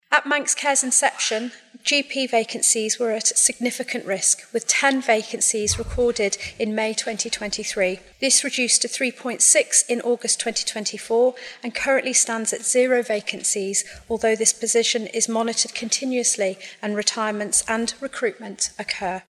Speaking in Tynwald this week, Health Minister Claire Christian said GP services have evolved in recent years to include more appointments with nurses and other healthcare professionals.